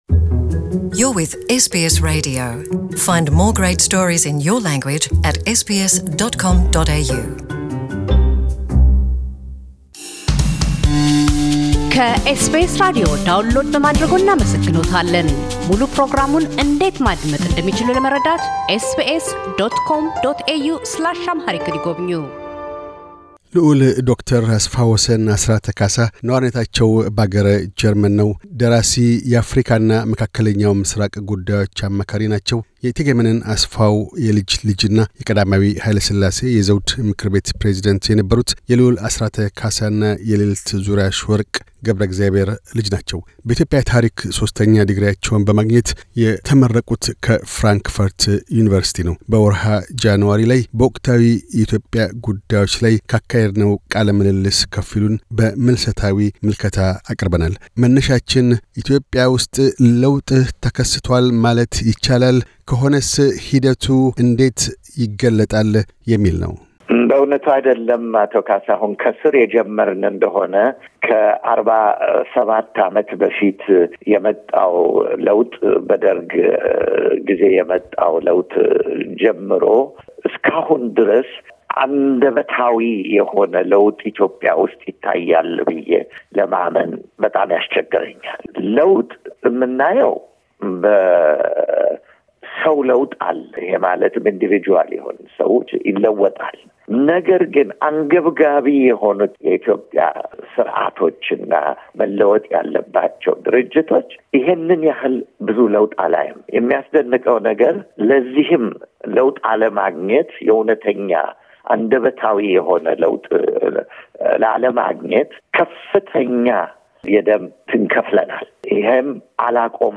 2021 ምልሰታዊ ምልከታ - ልዑል ዶ/ር አስፋ ወሰን አሥራተ ካሣ ነዋሪነታቸው በአገረ ጀርመን ሲሆን፤ ደራሲ፣ የአፍሪካና መካከለኛው ምሥራቅ ጉዳዮች አማካሪ ናቸው። በኢትዮጵያ ታሪክ ሶስተኛ ዲግሪያቸውን በማግኘት የተመረቁት ከፍራንክፈርት ዩኒቨርሲቲ ነው። በ2021 ጃኑዋሪ በኢትዮጵያ ወቅታዊ ጉዳዮች ዙሪያ ካካሄድነው ቃለ ምልልስ ከፊሉን በምልሰታዊ ምልከታ አቅርበናል።